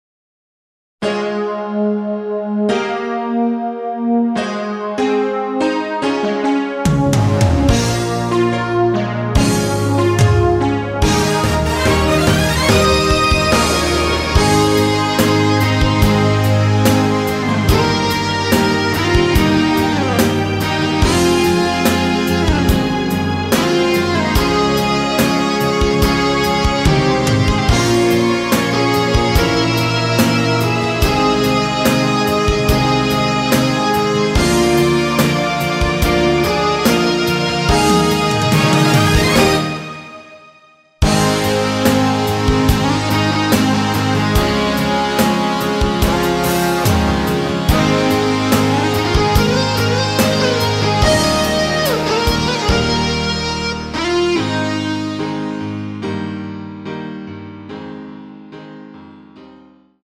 Ab
◈ 곡명 옆 (-1)은 반음 내림, (+1)은 반음 올림 입니다.
앞부분30초, 뒷부분30초씩 편집해서 올려 드리고 있습니다.